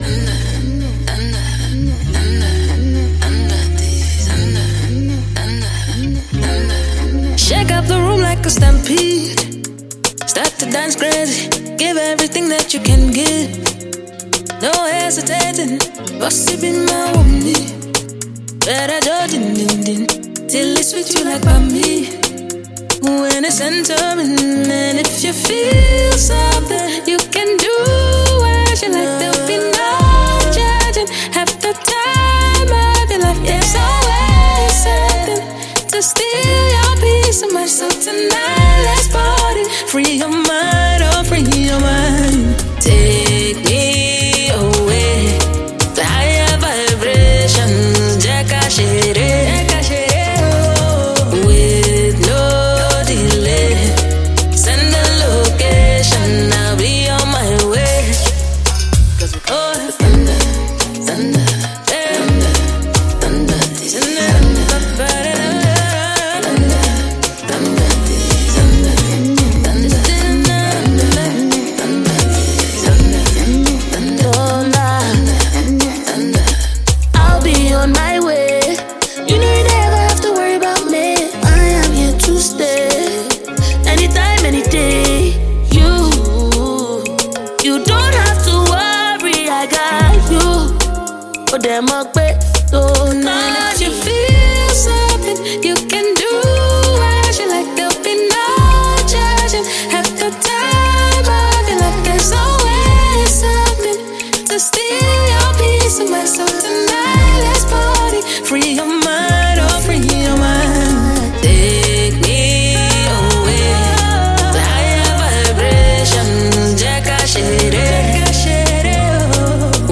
Talented Nigerian songstress, songwriter and composer
who added her melodious voice/vocals, to the amazing tune.